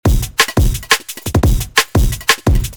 drum & bass samples
174BPM Drum Loop 1 Full
174BPM-Drum-Loop-1-Full.mp3